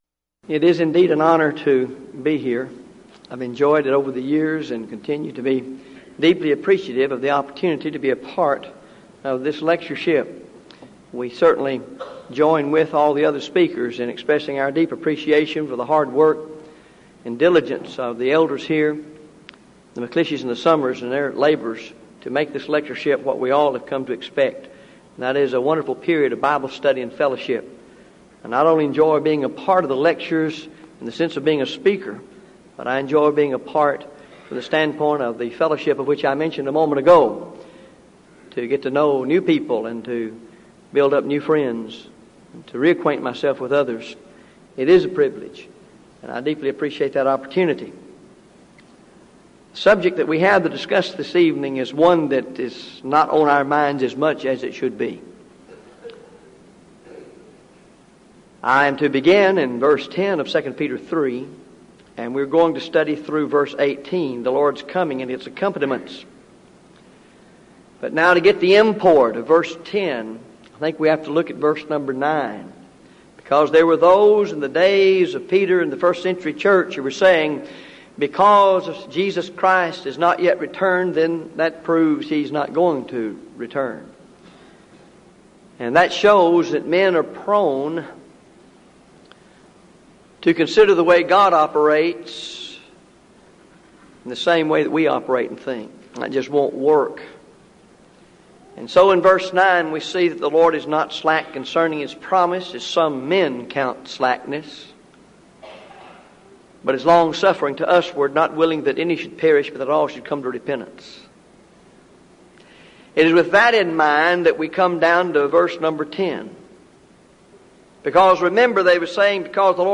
Event: 1998 Denton Lectures